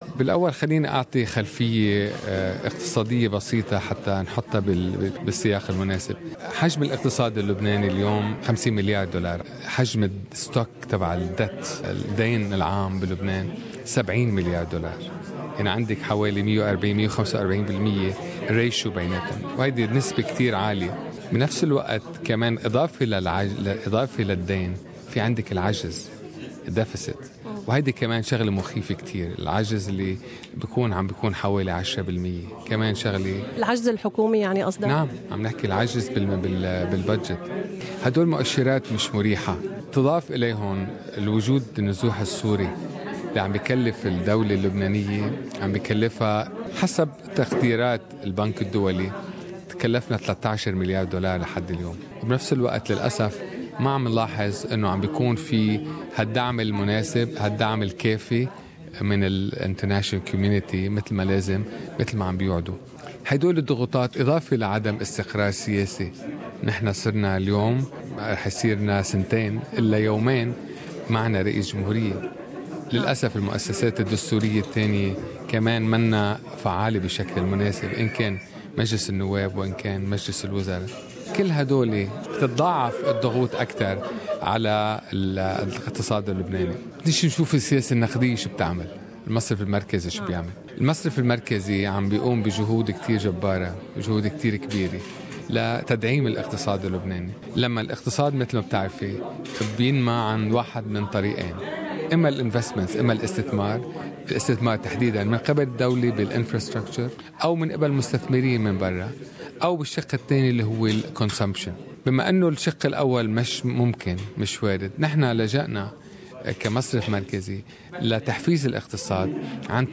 نائب حاكم مصرف لبنان يتحدث عن القطاع المالي في لبنان والتحديات الراهنة